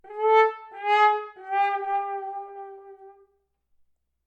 horn_fail_wahwah_3
fail fail-sound french-horn horn lose sad sad-trombone trombone sound effect free sound royalty free Memes